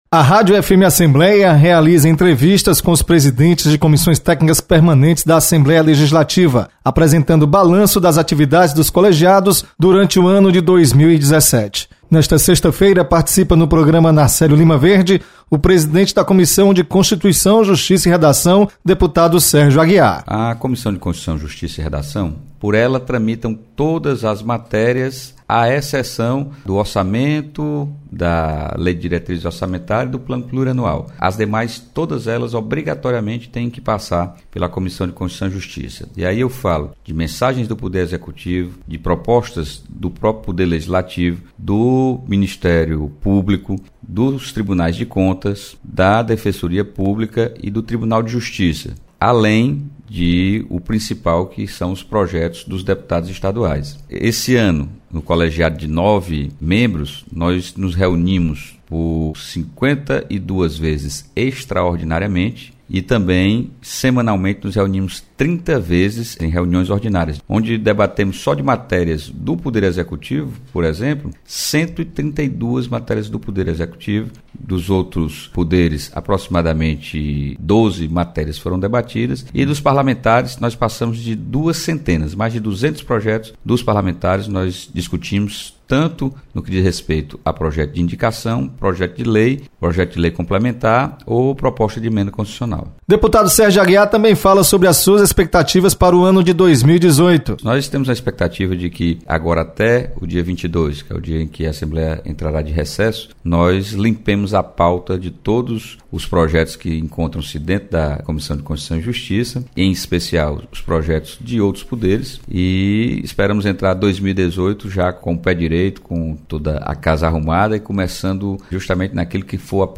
Deputado Sérgio Aguiar apresenta balanço da Comissão de Constituicão e Justiça.